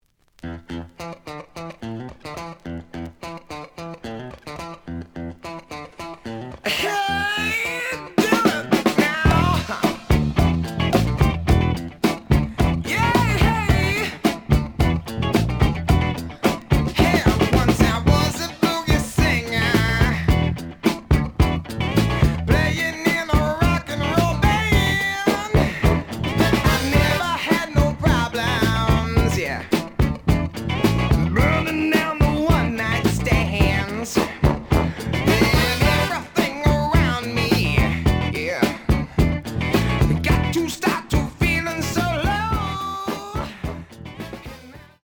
The audio sample is recorded from the actual item.
●Genre: Funk, 70's Funk
Edge warp.